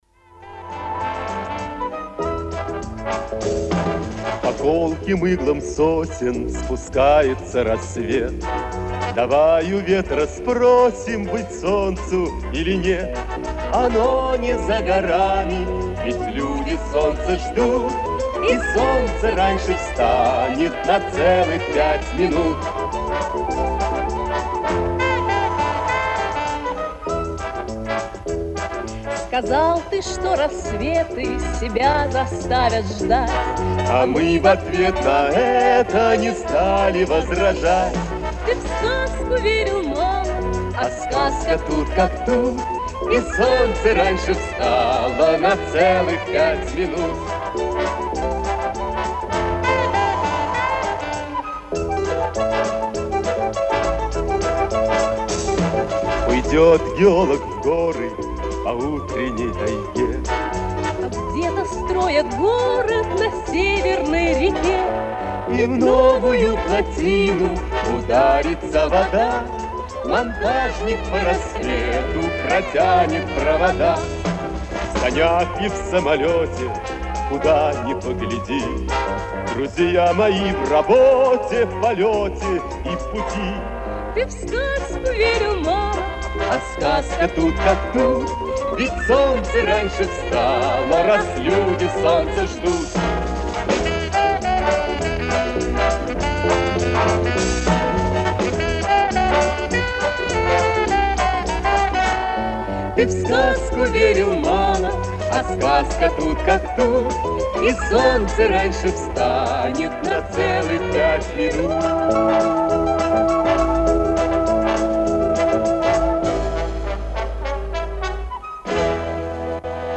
Записи эфирные с радио